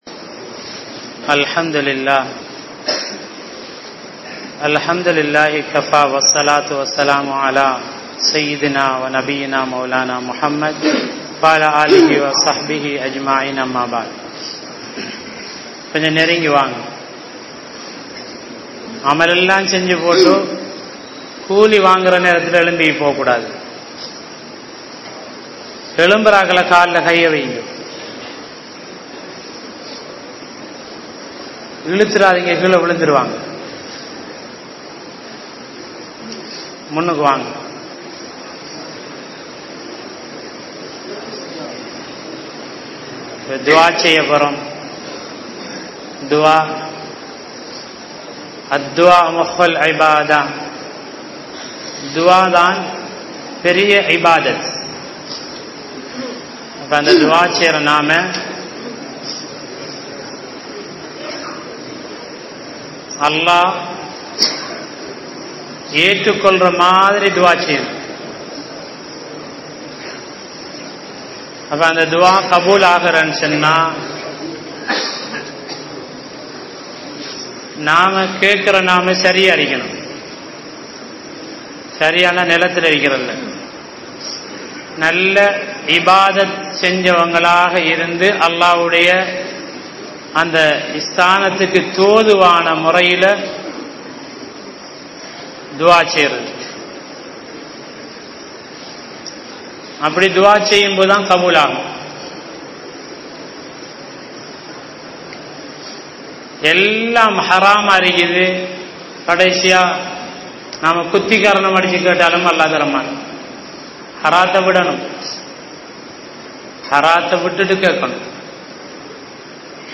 Thawba(27th Night) | Audio Bayans | All Ceylon Muslim Youth Community | Addalaichenai
Grand Jumua Masjith